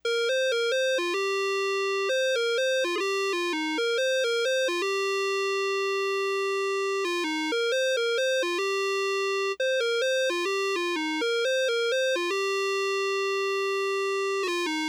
01 lead.wav